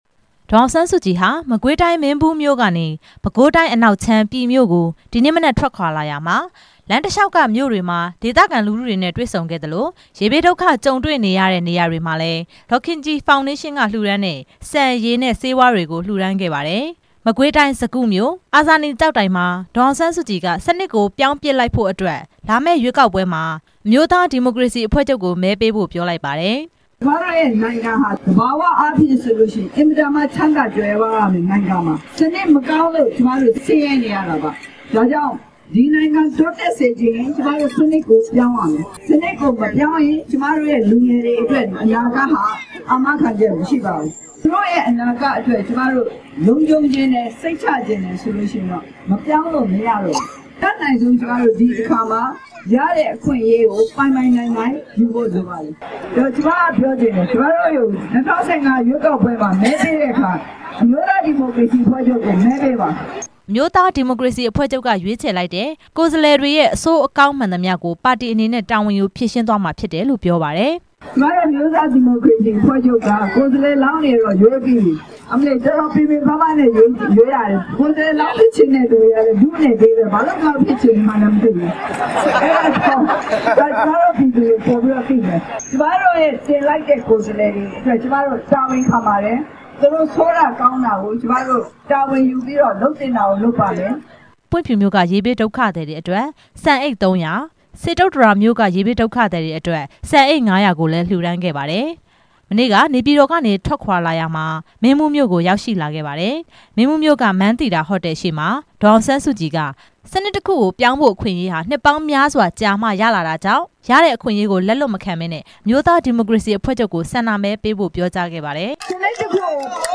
မကွေးတိုင်းဒေသကြီး စကုမြို့က အာဇာနည်ကျောက်တိုင်မှာ ဒေသခံတွေနဲ့ တွေ့ဆုံစဉ်ပြောခဲ့တာ ဒေါ်အောင်ဆန်း စုကြည်က ပြောလိုက်တာဖြစ်ပါတယ်။